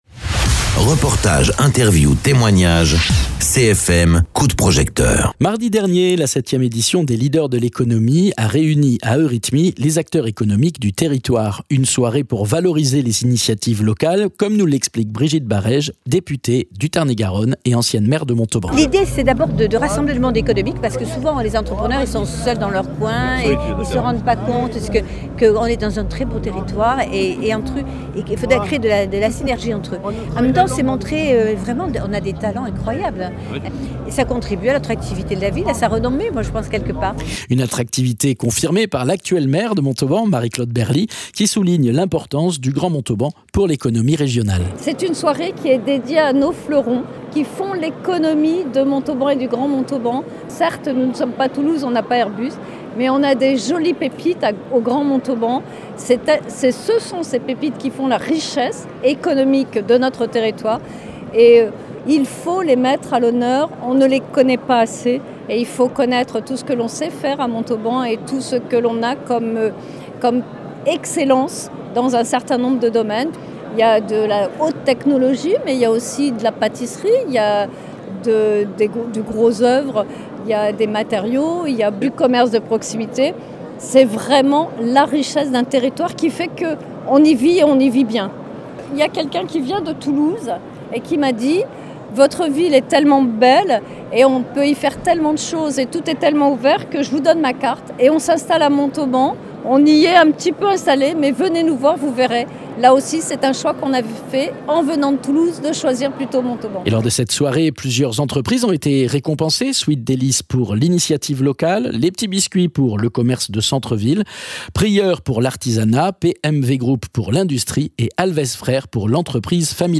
Interviews
Mardi dernier, à Montauban, la 7e édition des Leaders de l’économie a réuni les acteurs économiques du territoire. Une soirée essentielle pour valoriser les initiatives locales, comme nous l’explique Brigitte Barèges, députée et ancienne maire de Montauban.
Une attractivité confirmée par l’actuelle maire de Montauban, Marie-Claude Berly, qui souligne l’importance du Grand Montauban pour l’économie régionale.